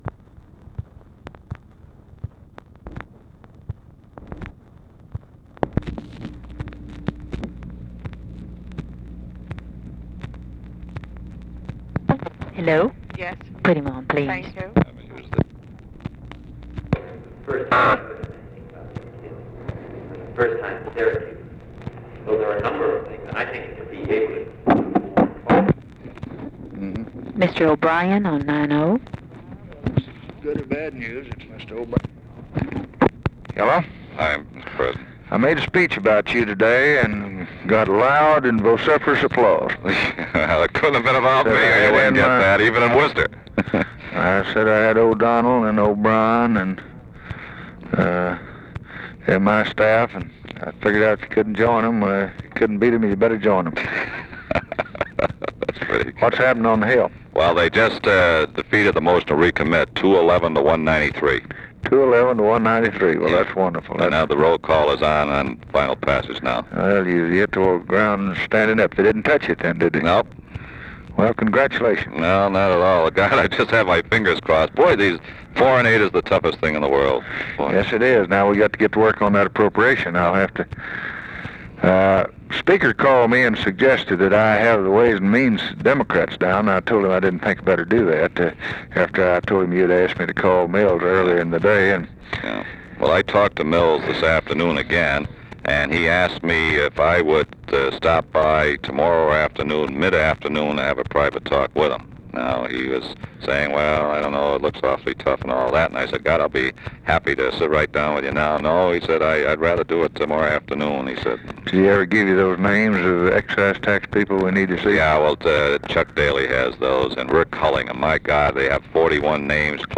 Conversation with LARRY O'BRIEN and OFFICE CONVERSATION, June 10, 1964
Secret White House Tapes